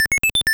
level_up.wav